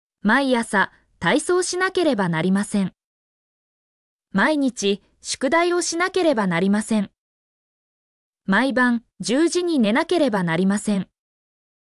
mp3-output-ttsfreedotcom-77_cAVw1Opp.mp3